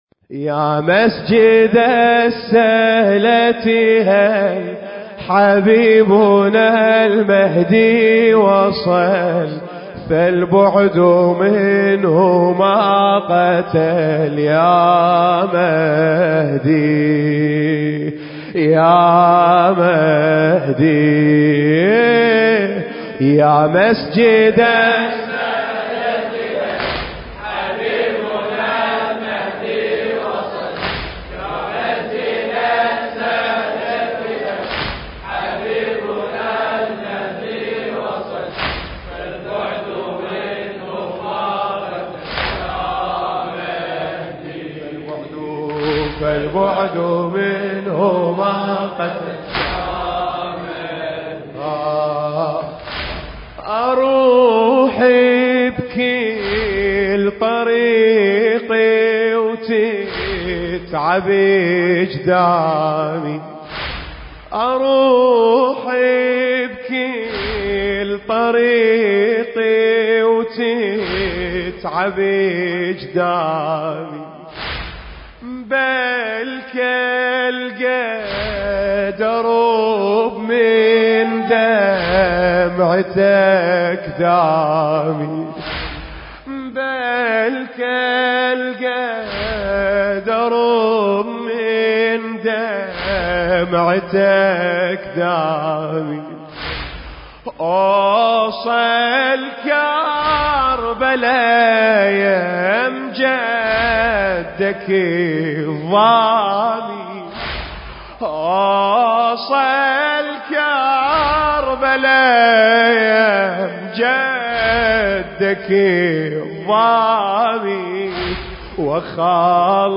المكان: رابطة عشاق الكوفة/ واسط – ناحية الأحرار